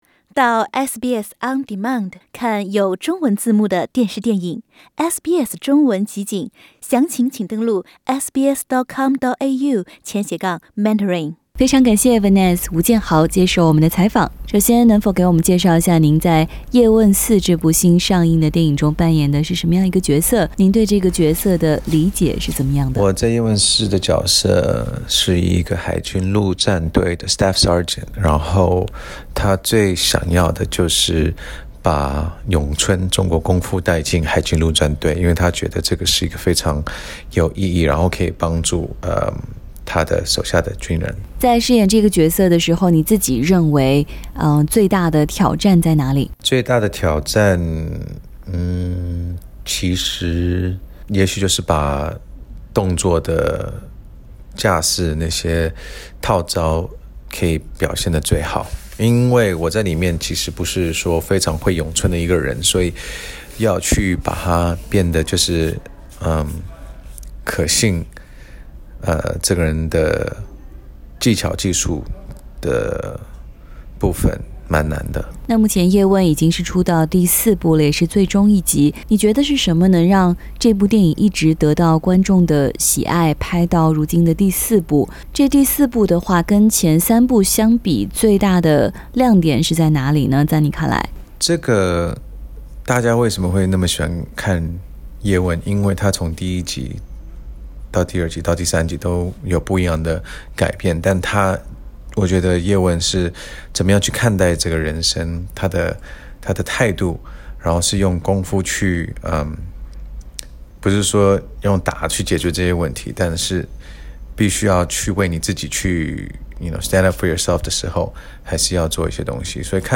叶问4演员吴建豪专访：能强化海外华人的身份认同